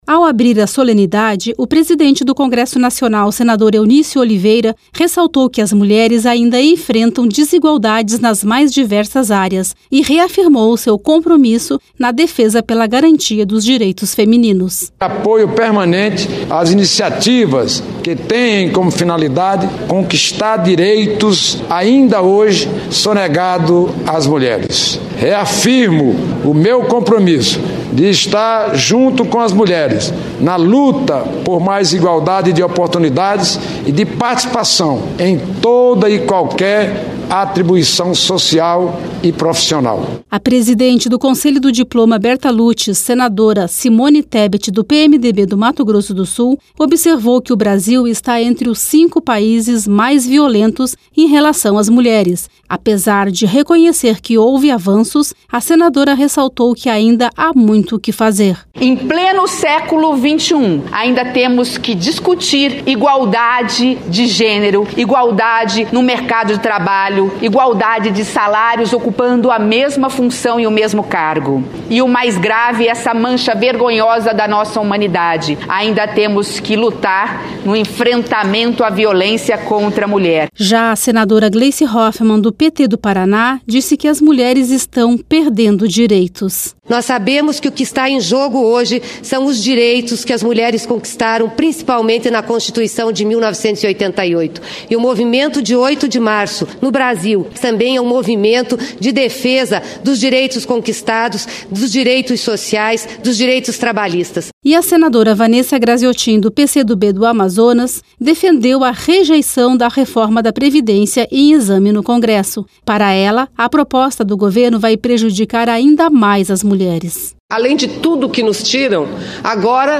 Já a presidente do Conselho do Diploma Bertha Lutz, senadora Simone Tebet (PMDB-MS), observou que o Brasil está entre os cinco países mais violentos em relação às mulheres. Durante a sessão, os participantes promoveram um “apitaço” em apoio ao movimento “Paro”, iniciativa que aconteceu em mais de 60 países e em 20 estados brasileiros, onde mulheres pararam suas atividades com o objetivo de ressaltar a defesa dos direitos femininos.